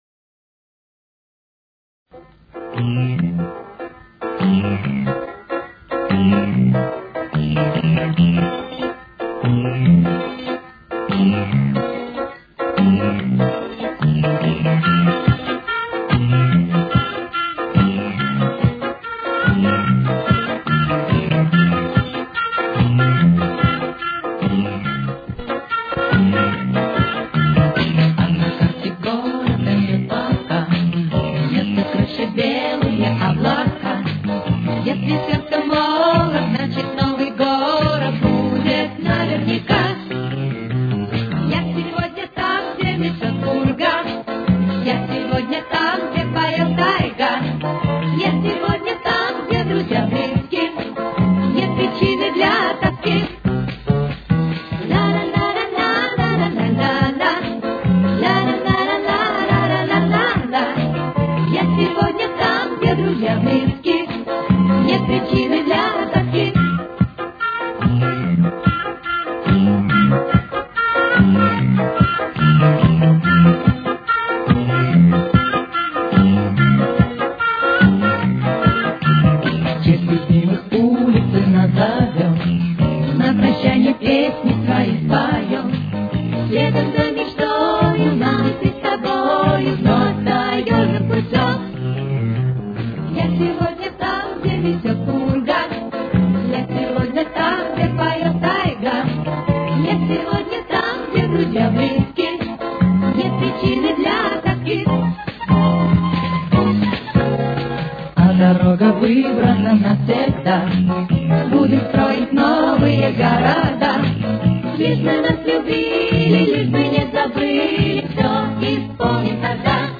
с очень низким качеством (16 – 32 кБит/с)
Тональность: Ми минор. Темп: 147.